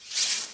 attack.ogg